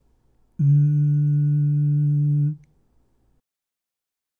次に、巨人の喉頭の状態のまま、グー/チョキ/パーそれぞれの声で「ん」と発声して下さい。
※喉頭は巨人状態のチョキの声(ん)